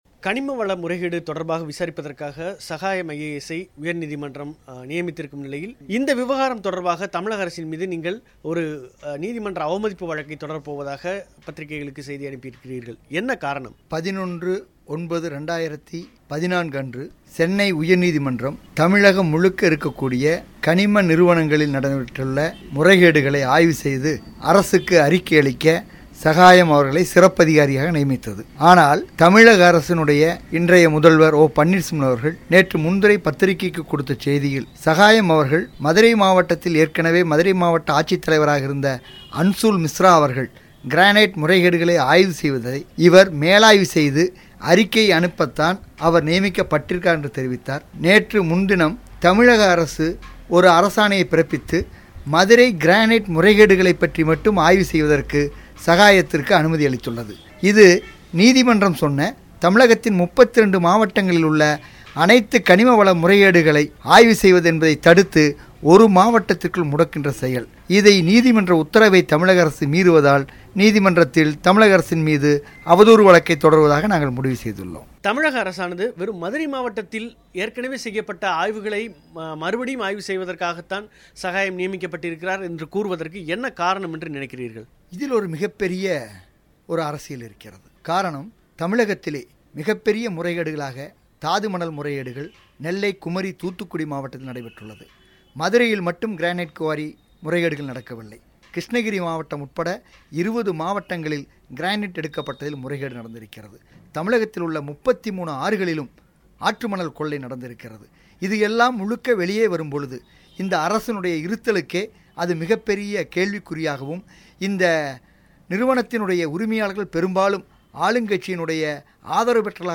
இது குறித்து அவர் பிபிசி தமிழோசைக்கு அளித்த விரிவான பேட்டியை நேயர்கள் இங்கே கேட்கலாம்.